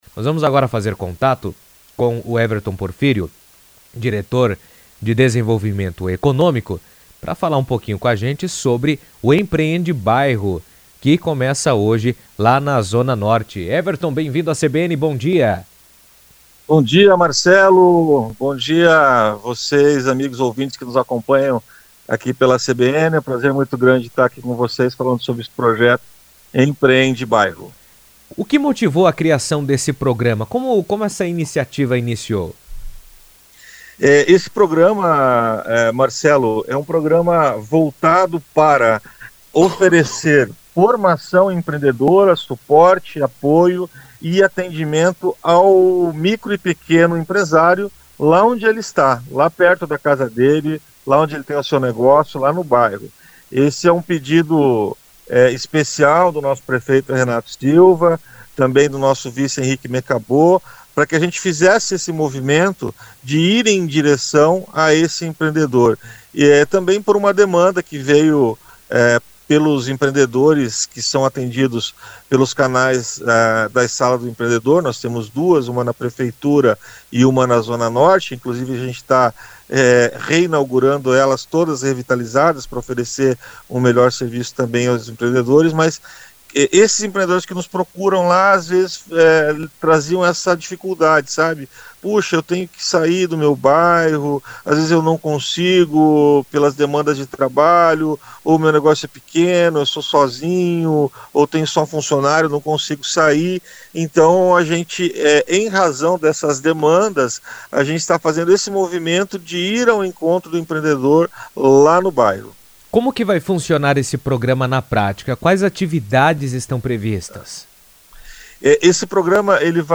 Em entrevista ao vivo por telefone à CBN Cascavel